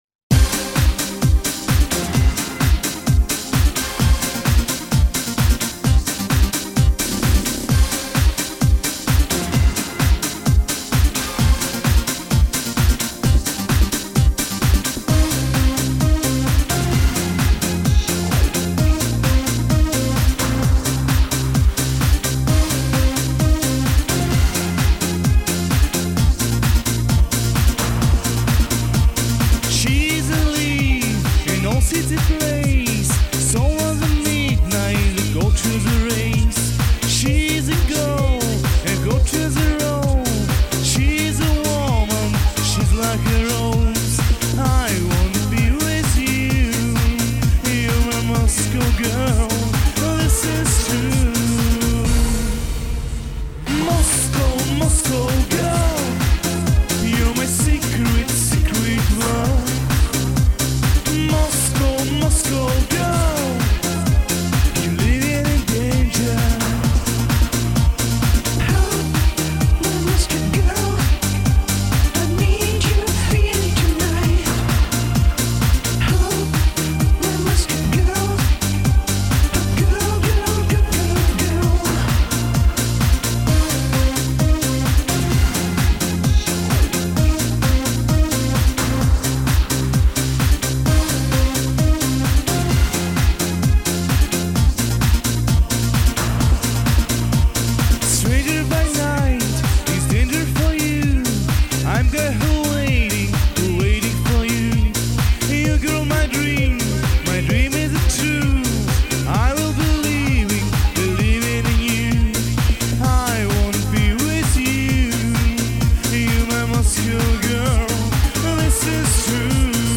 Genre: Dream.